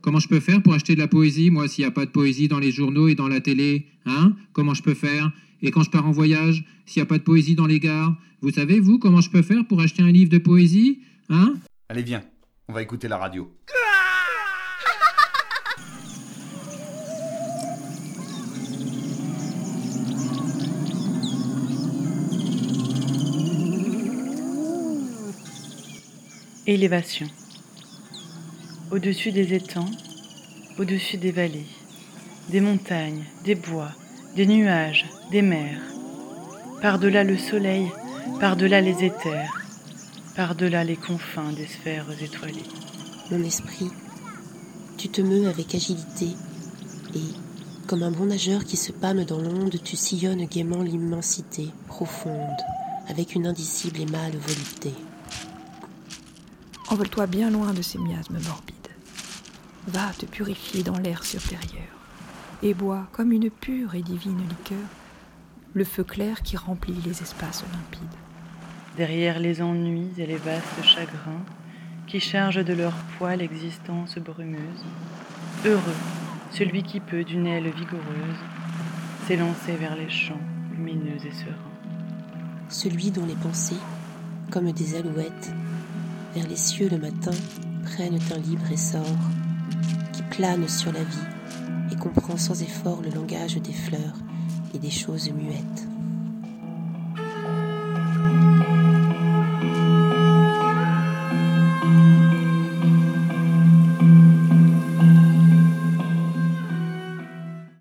Durant le confinement la brigade poétique éphémère nous propose une lecture par semaine.